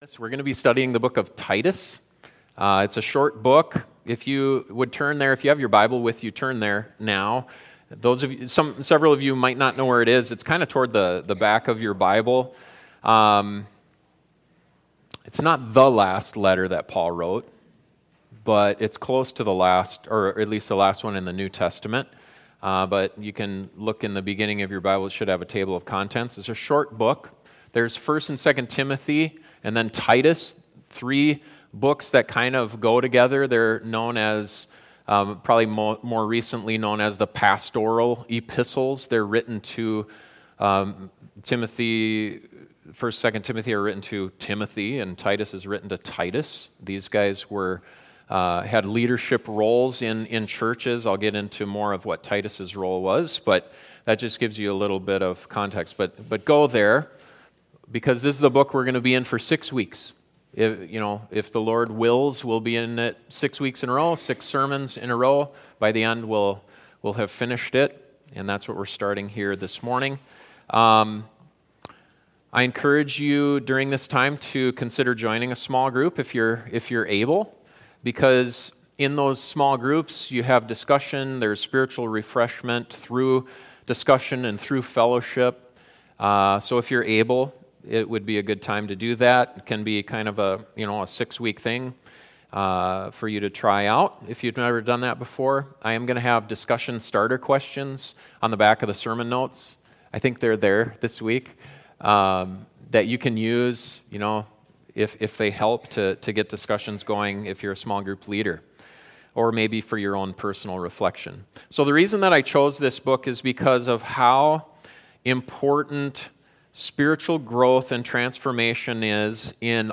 An introduction to a new sermon series on the book of Titus. In a world that’s becoming more resistant to Christianity, how do we continue to make an impact for the Gospel?